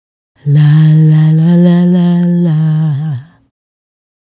La La La 110-D#.wav